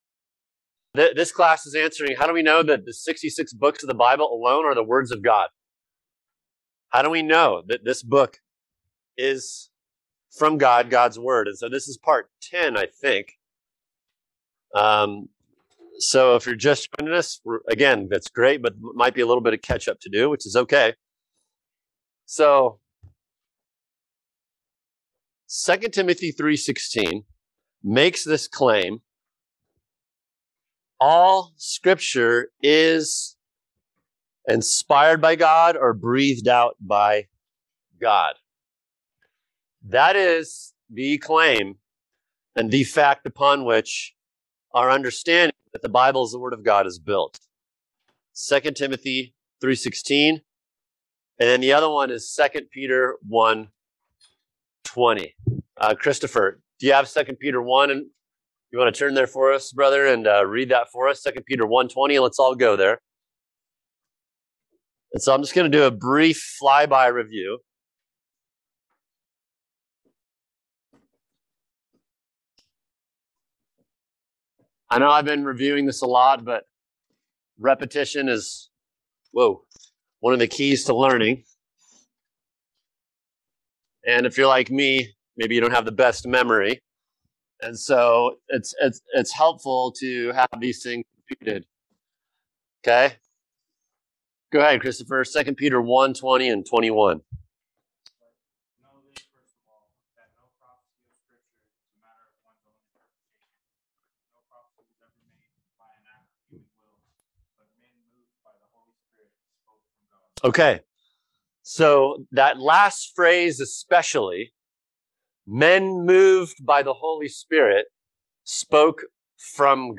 [equipping class] Bibliology Lesson 9 – The Biblical Text | Cornerstone Church - Jackson Hole